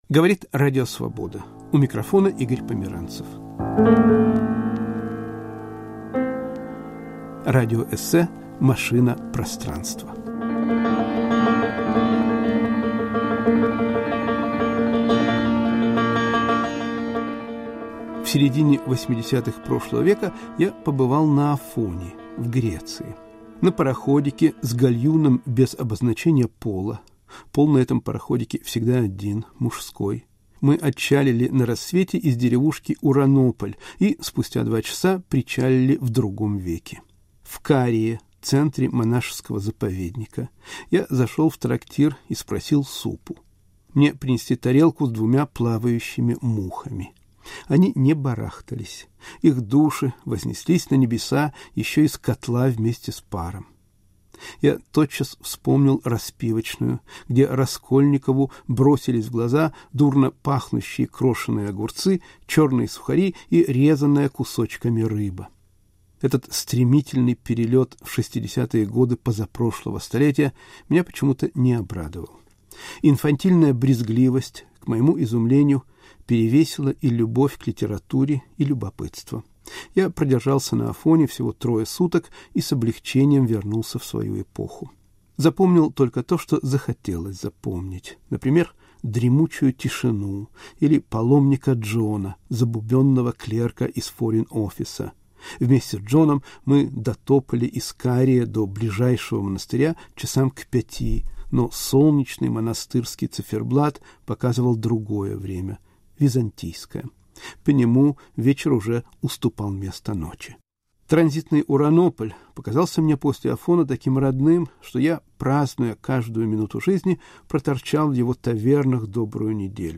Радиоэссе "Машина пространства"